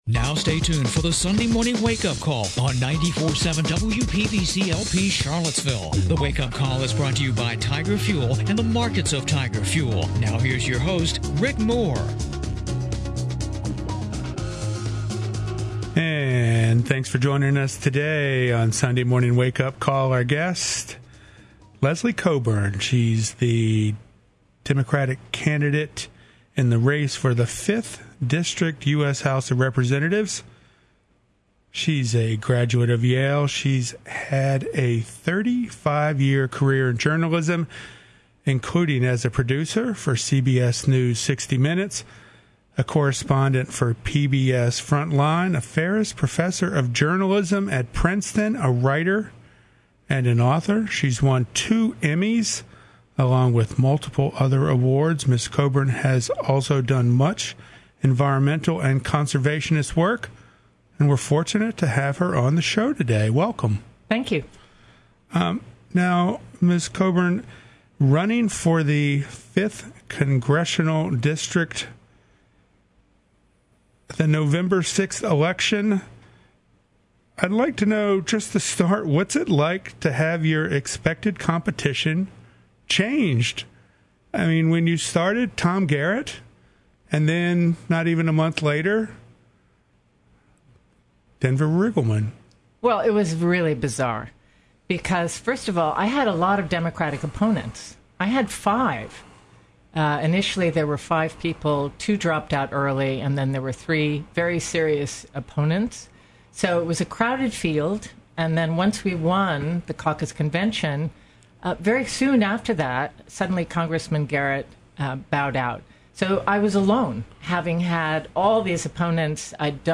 WPVC Special: A Job Interview